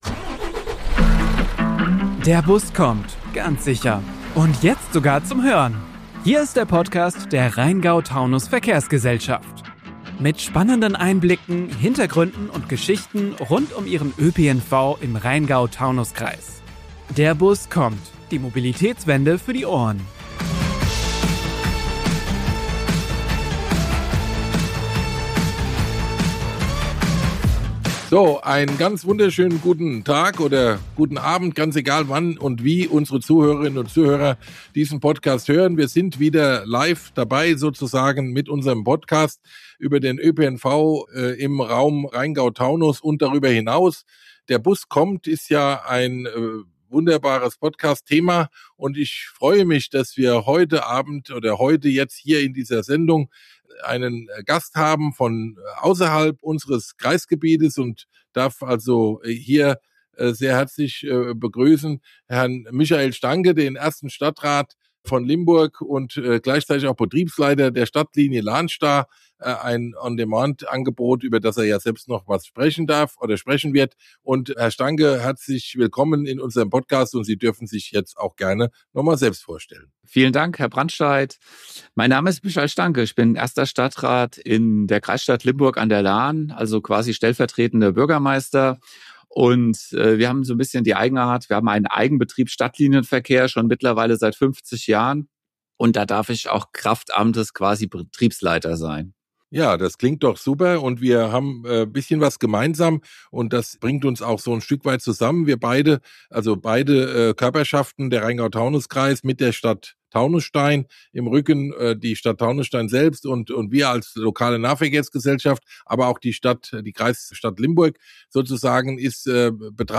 Im Mittelpunkt des Mobilitätstalks steht das On-Demand-Angebot „LahnStar“ und damit die Frage, wie moderner ÖPNV in Mittelzentren und im ländlichen Raum zukunftsfähig gestaltet werden kann. Seit 2021 ist Limburg Teil des Pilotprojekts des Rhein-Main-Verkehrsverbunds für On-Demand-Verkehre.